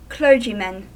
Ääntäminen
Ääntäminen US : IPA : [ˈklɝ.dʒi.mɛn] Tuntematon aksentti: IPA : /ˈklɝ.dʒi.mən/ IPA : /ˈklɜː.dʒi.mən/ Haettu sana löytyi näillä lähdekielillä: englanti Käännöksiä ei löytynyt valitulle kohdekielelle.